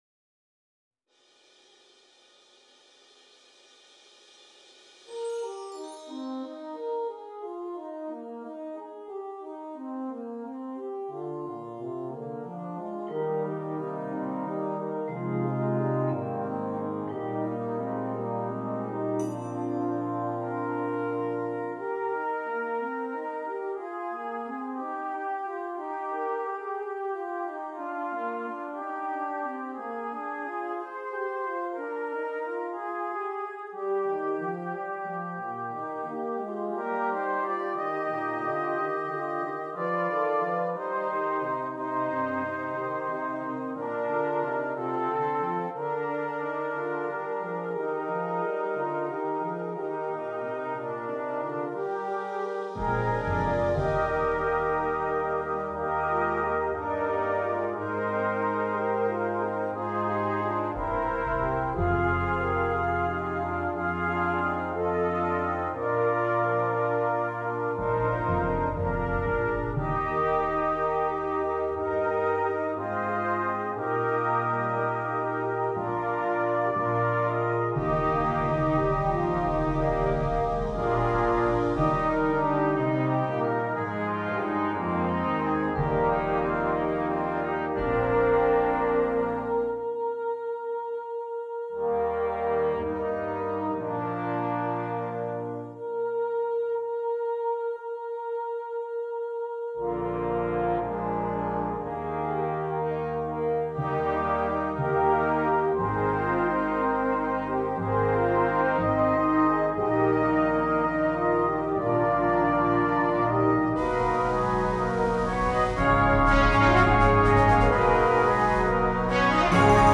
Voicing: 11 Brass and Percussion